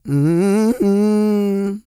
E-SOUL 304.wav